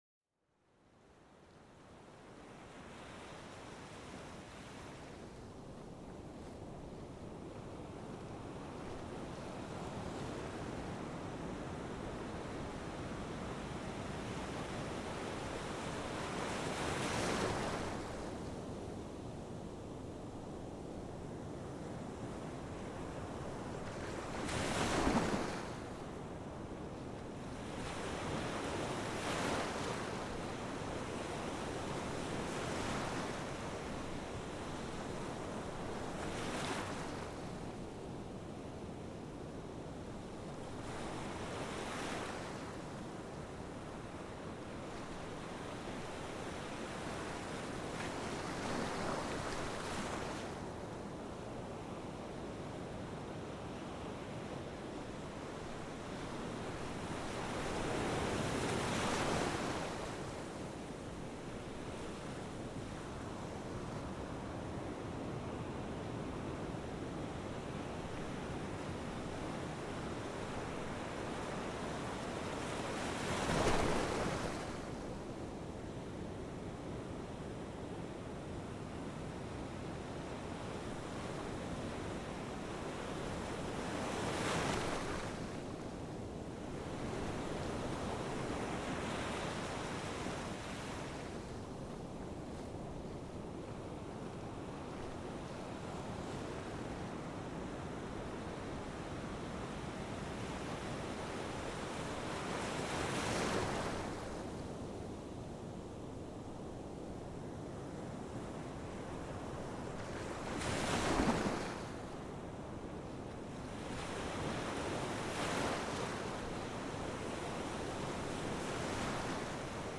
Las olas del océano para una relajación reconfortante